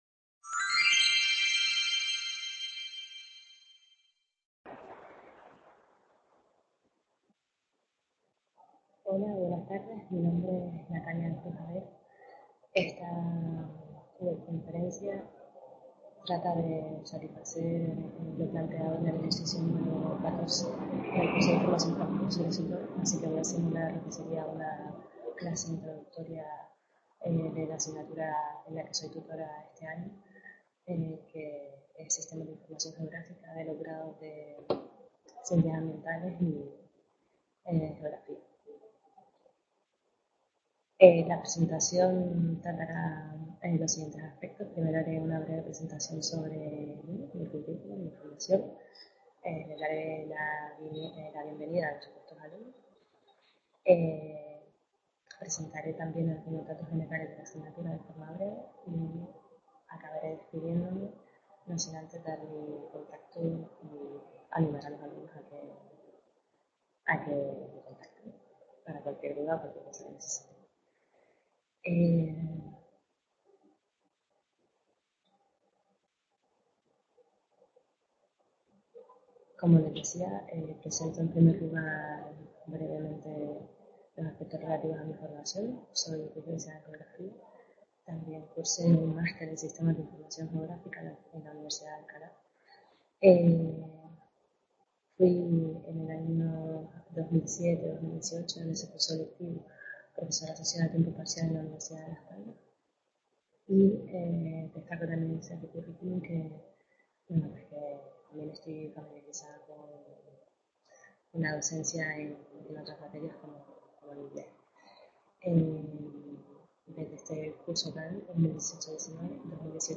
Simulación de una tutoría online de la asignatura Sistemas de Información Geográfica para completar actividad n14 del curso de profesores tutores
Video Clase